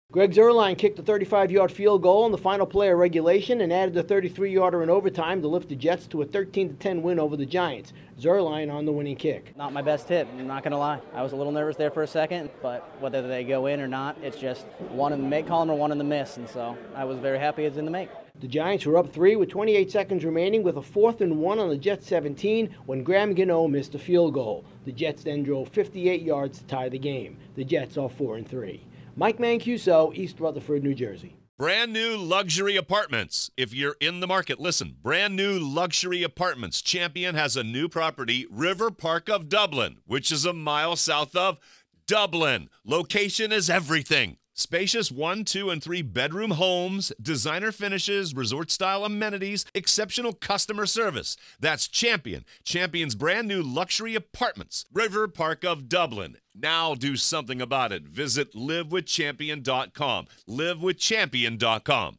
A pair of late field goals send the Jets past the Giants. Correspondent